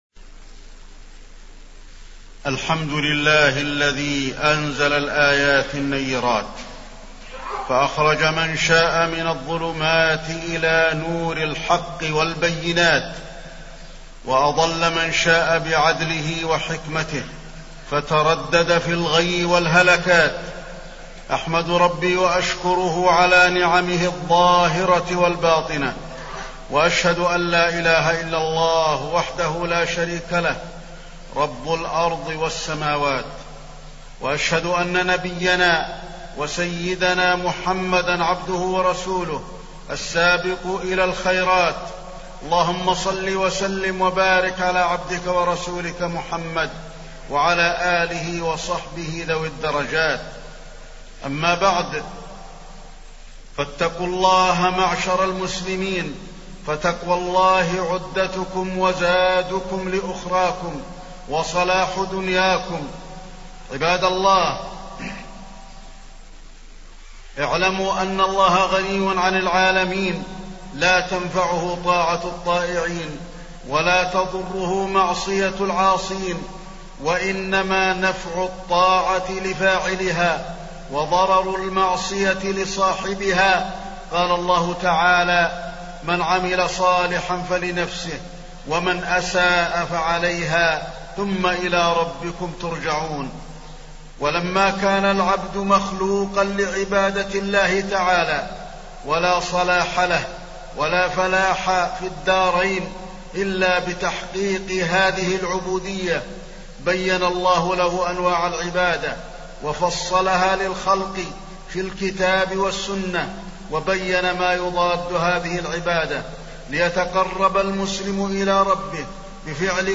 تاريخ النشر ٦ ذو القعدة ١٤٢٨ هـ المكان: المسجد النبوي الشيخ: فضيلة الشيخ د. علي بن عبدالرحمن الحذيفي فضيلة الشيخ د. علي بن عبدالرحمن الحذيفي الإخلاص في العمل واتباع السنة